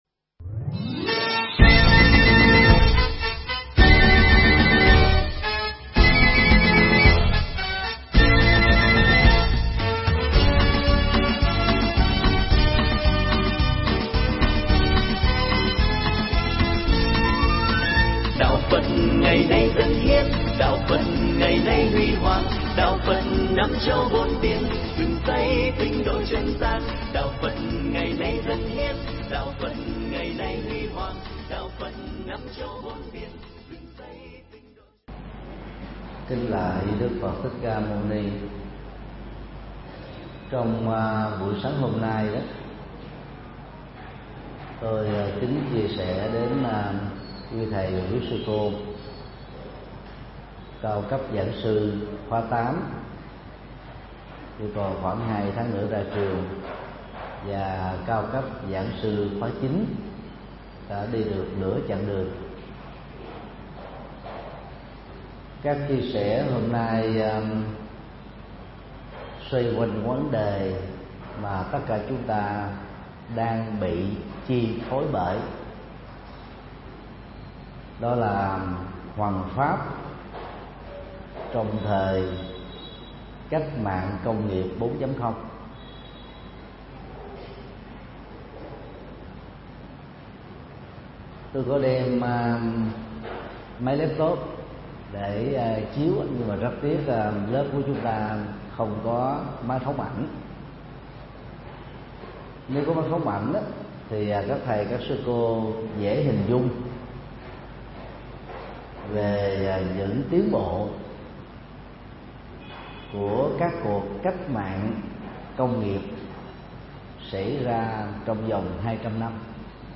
Mp3 Pháp Thoại Hoằng Pháp Trong Thời Cách Mạng Công Nghiệp 4.0
giảng tại chùa Hòa Khánh, Bình Thạnh